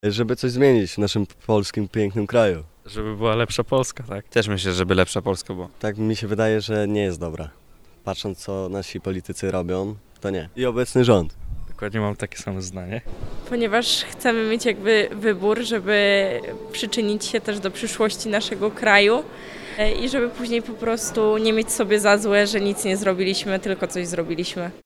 Zbliżająca się druga tura wyborów prezydenckich w Polsce budzi emocje, nie tylko w mediach, ale i wśród młodych ludzi. Zapytaliśmy młodzież: dlaczego chcą zagłosować? Wielu uczestników sondy podkreślało, że głosowanie jest ich sposobem na próbę zmiany rzeczywistości: